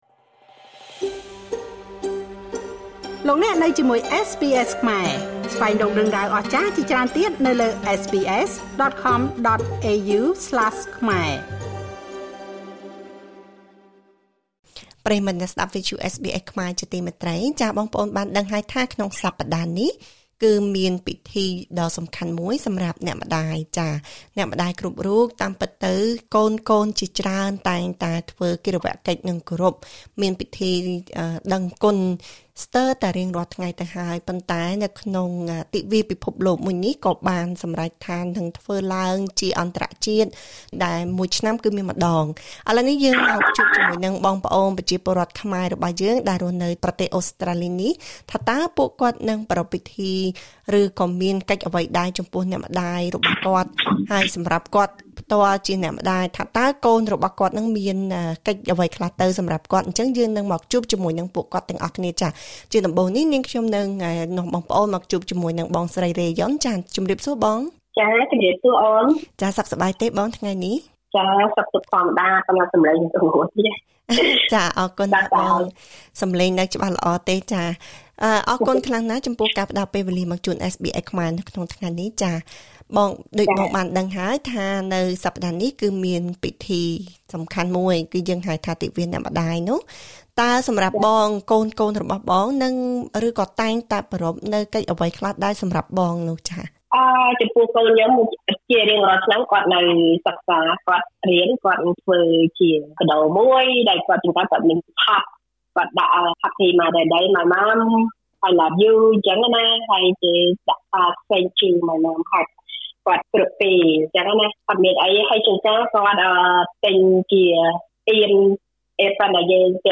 ទិវាអ្នកម្តាយនៅប្រទេសអូស្រ្តាលីក្នុងឆ្នាំ ២០២៤ នឹងប្រារព្ធធ្វើឡើងនៅថ្ងៃអាទិត្យ ទី១២ ខែមេសា។ នេះជាពិធីអន្តរជាតិមួយ ដើម្បីគោរពដឹងគុណដល់មាតាទាំងអស់ ដែលជាបុគ្គលសំខាន់ក្នុងគ្រួសារ។ តើមានកិច្ចអ្វីខ្លះដែលអ្នកម្តាយទទួលបានក្នុងថ្ងៃនេះ? សូមស្តាប់បទសម្ភាសន៍ជាមួយអ្នកម្តាយកម្ពុជាអូស្រ្តាលីបីរូប ដែលនឹងរៀបរាប់អំពីបទពិសោធន៍របស់ពួកគេក្នុងថ្ងៃទិវាអ្នកម្តាយ។